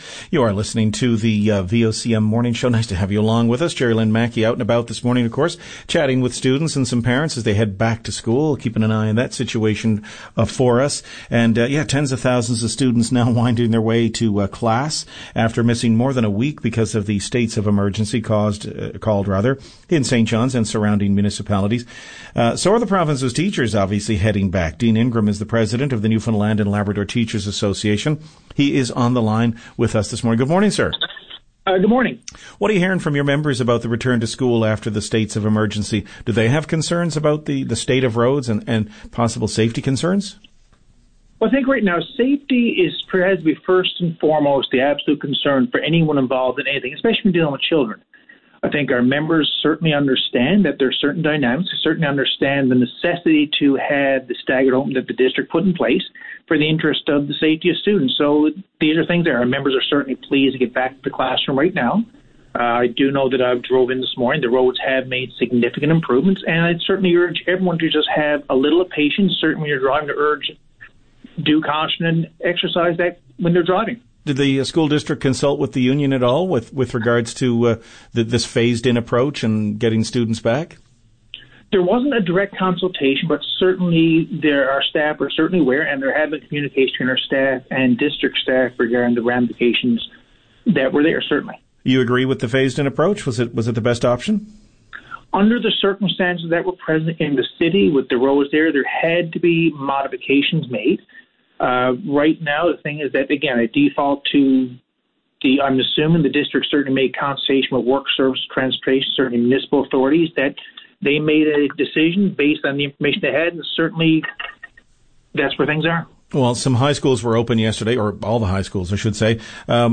Media Interview - VOCM Morning Show Jan. 28, 2020